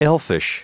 Prononciation du mot elfish en anglais (fichier audio)
Prononciation du mot : elfish